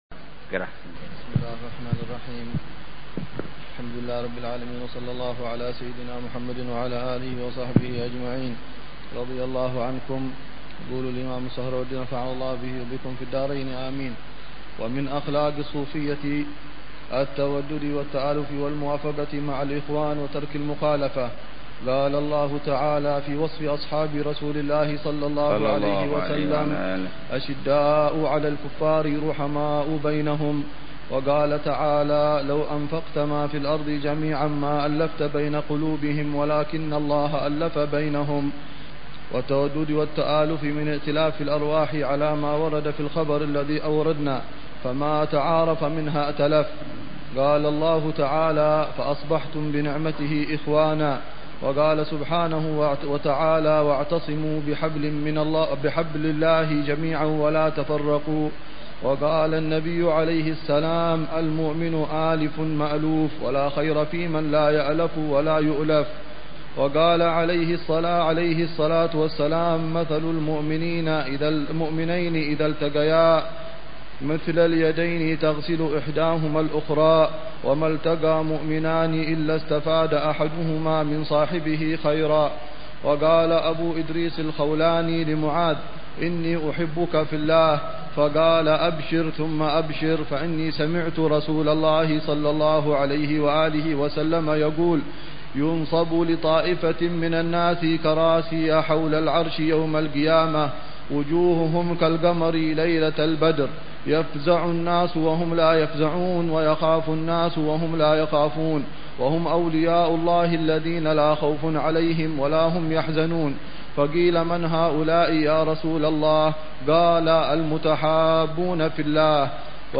شرح لكتاب عوارف المعارف للإمام السهروردي ضمن دروس الدورة التعليمية الثانية عشرة والثالثة عشرة بدار المصطفى في صيف عامي 1427هـ و 142